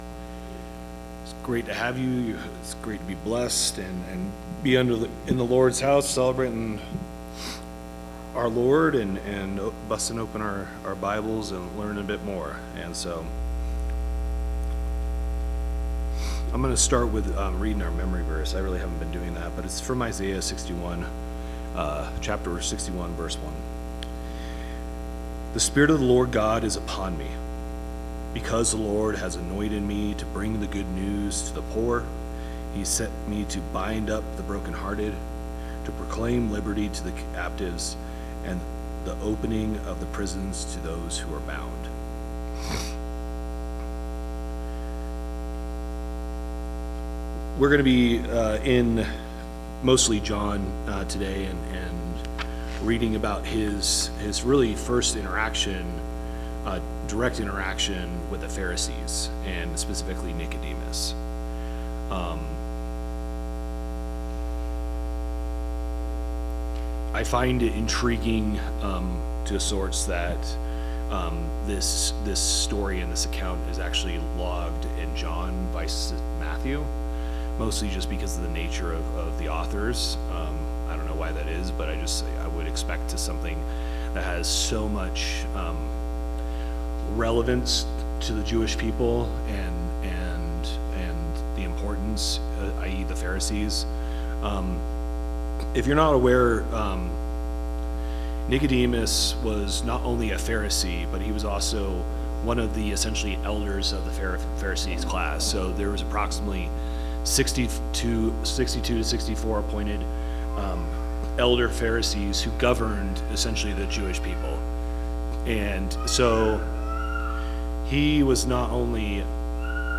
Bible Class 04/05/2026 - Bayfield church of Christ
Sunday AM Bible Class